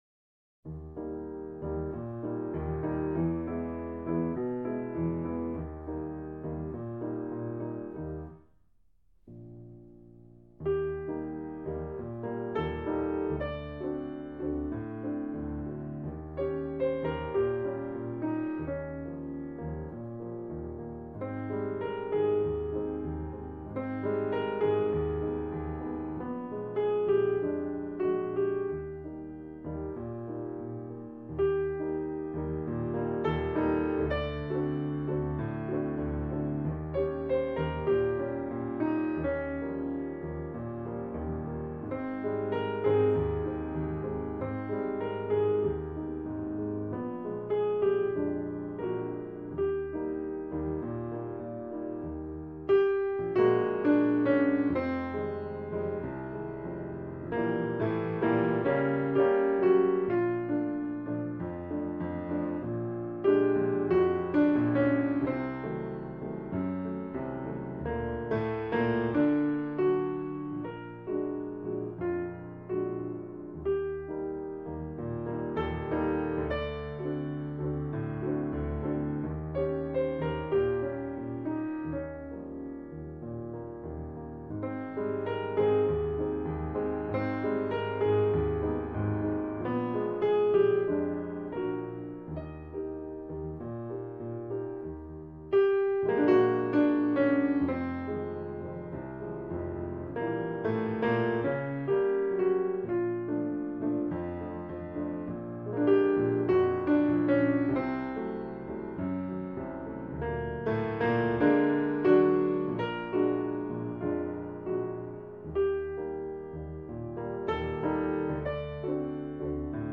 เปียโน, เพลงพระราชนิพนธ์